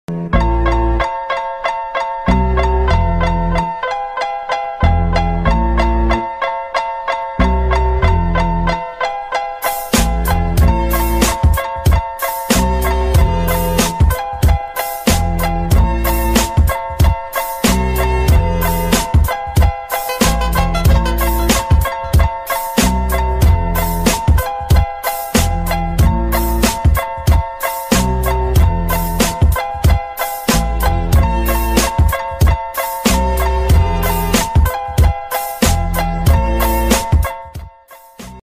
تنزيل رنة موبايل أجنبية أغنية هيب هوب مشهورة
موسيقى بدون غناء